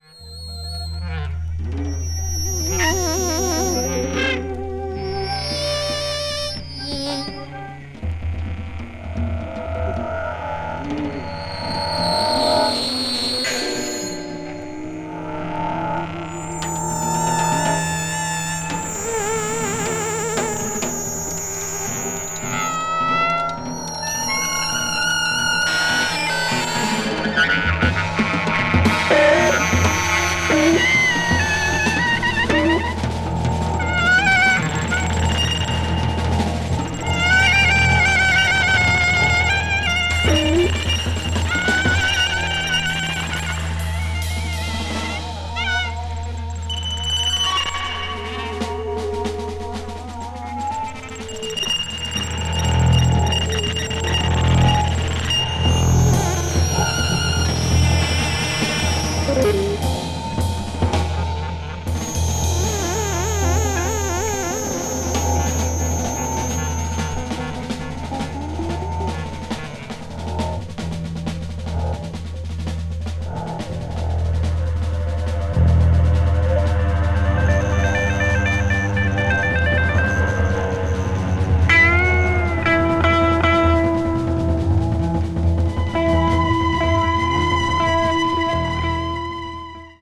a group of German and Dutch jazz musicians
avant-jazz   experimental jazz   jazz rock   spritual jazz